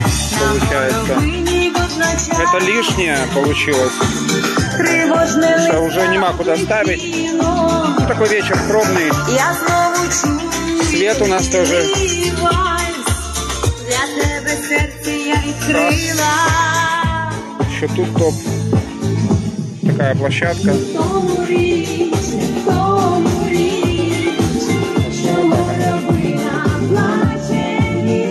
Караоке вечори в Одесі